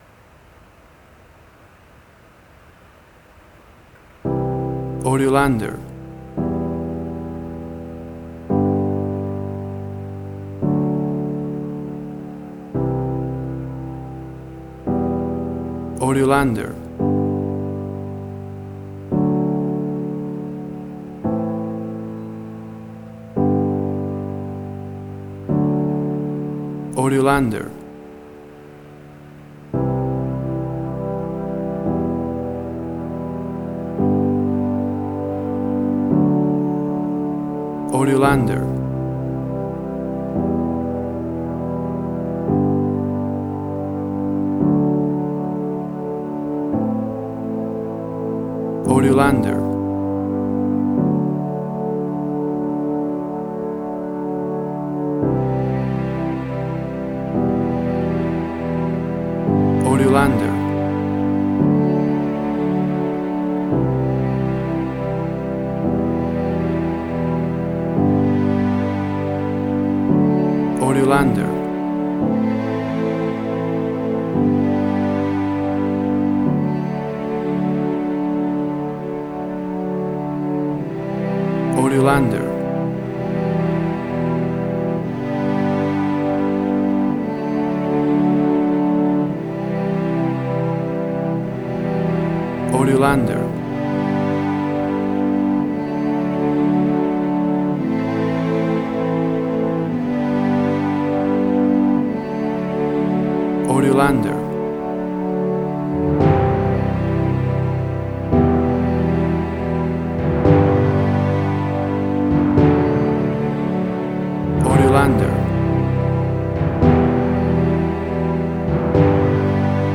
Suspense, Drama, Quirky, Emotional.
Tempo (BPM): 113